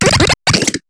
Cri de Larvibule dans Pokémon HOME.